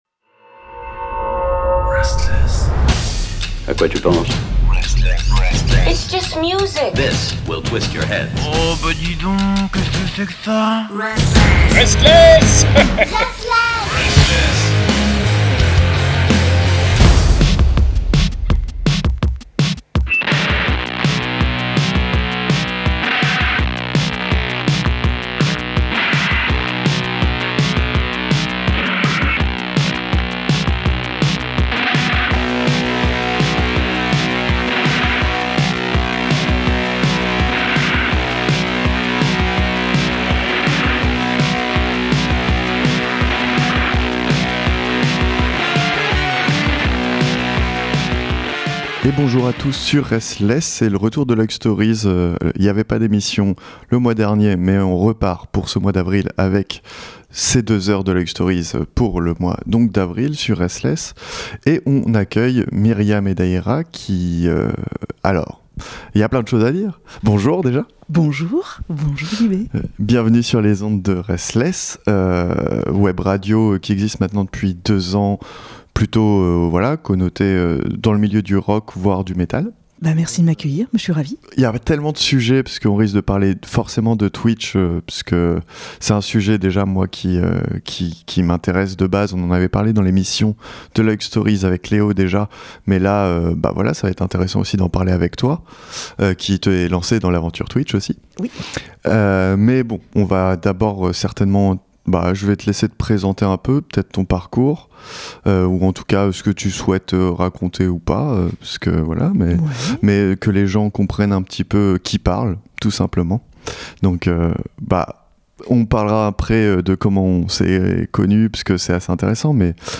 Luik Stories c’est l’émission belge qui explique le monde de la musique avec des artistes en invités. C’est tous les 2ème et 4ème mercredis du mois à 20h sur RSTLSS Radio.